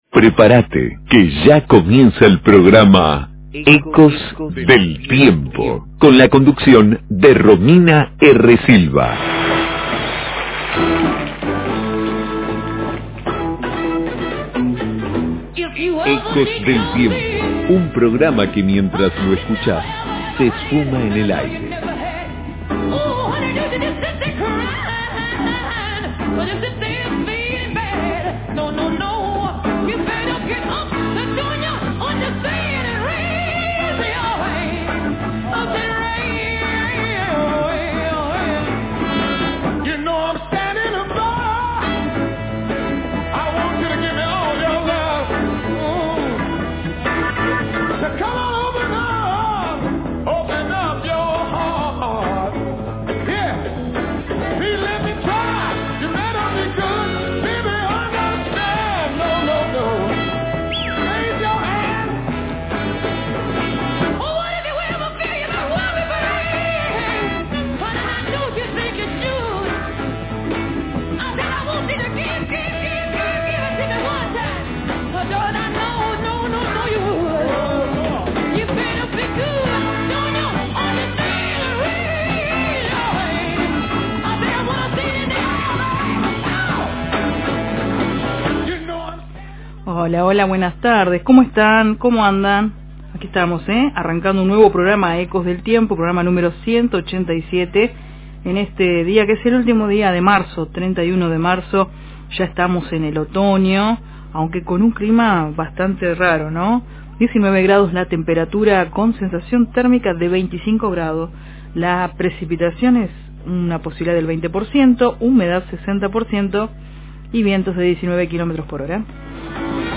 Entrevista en vivo